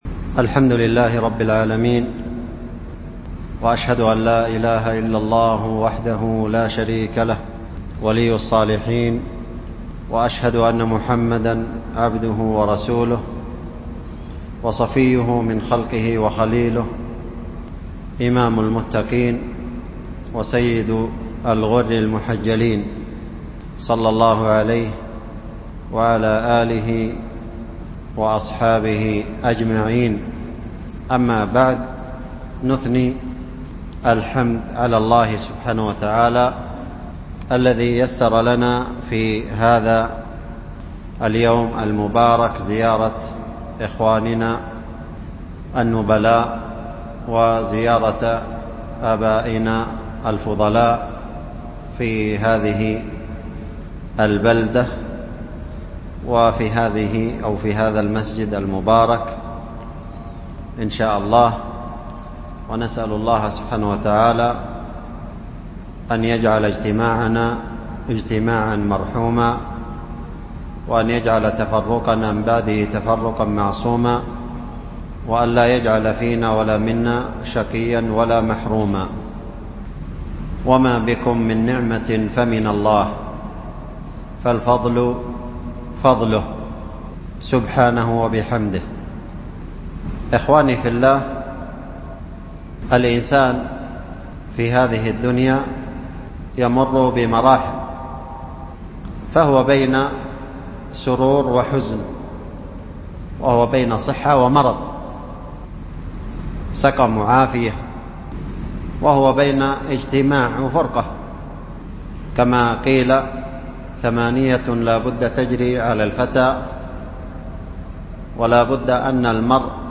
محاضرة بين مغرب وعشاء في طائفة من أحكام المريض، وفيه ذكر فضل المرض وما يكفر من الذنوب، وأهمية الاحتساب، وطائفة من أداب المريض و أهله، وفضل عيادته ، وآدابها . ألقيت في السوم من وادي حضرموت .